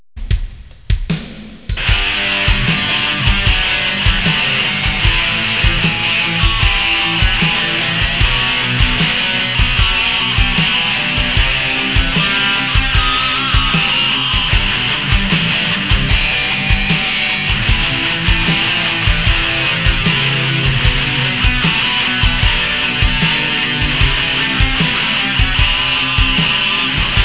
G major arpeggio type 2
I played the G major, Am, Bm and Chord in the background and then played the first 4 arpeggios under each chord. I played each of the first 4 arpeggios once and the second time around I played them using a different timing.
I also used the electric guitar with distortion added, but you can play this with an acoustic guitar too. I must apologize for the quality of the recording though.
Listen to the first 4 G, Am, Bm and C.